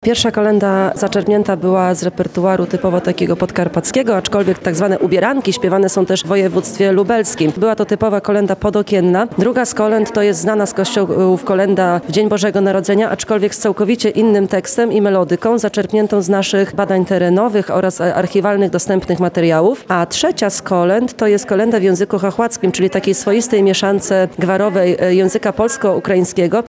W Lublinie trwa XVI Ogólnopolski Przegląd Zespołów i Grup Kolędniczych im. Zdzisława Podkańskiego. Swoje talenty wokalne i teatralne prezentuje 35 zespołów.